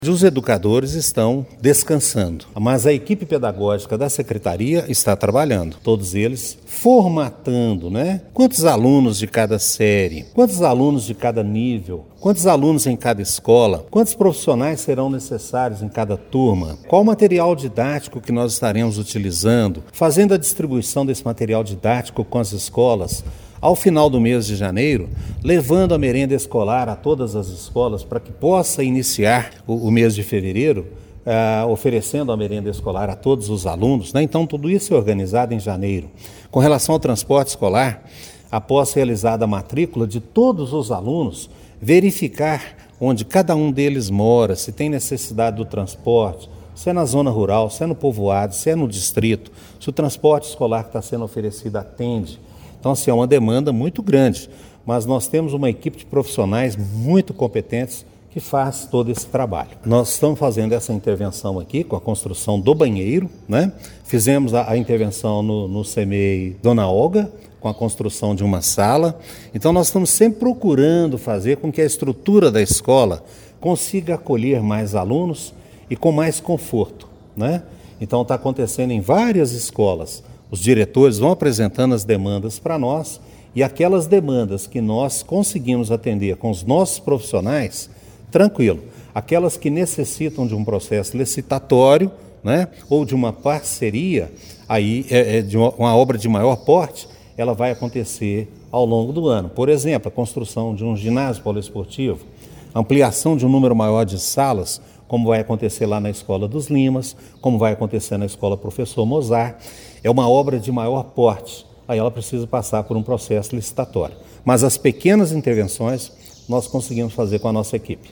Nesta terça-feira (20), durante coletiva de imprensa no CMEI Cônego Gabriel Hugo da Costa Bittencourt, o secretário Marcos Aurélio dos Santos detalhou as ações que preparam as 34 escolas da cidade para o retorno das atividades.